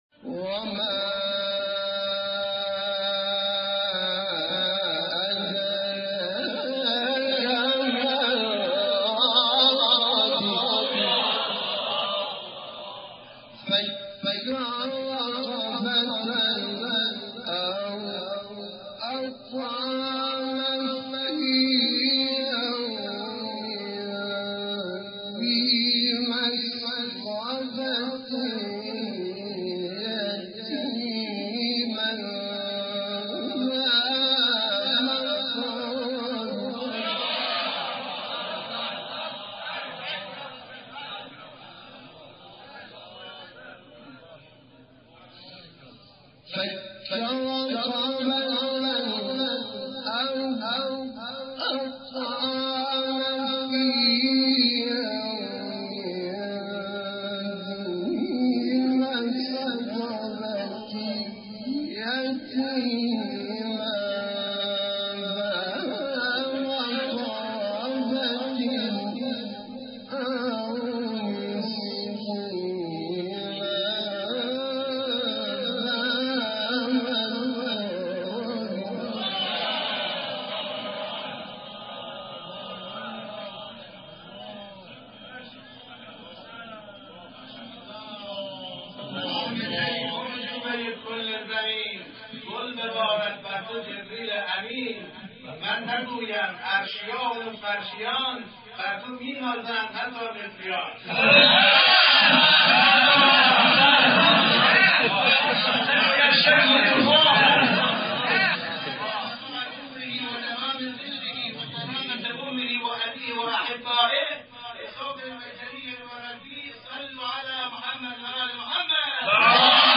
آیه 12-20 سوره بلد استاد حامد شاکرنژاد سبک محمد رفعت | نغمات قرآن
سوره : بلد آیه : 12-20 استاد : حامد شاکرنژاد مقام : بیات قبلی بعدی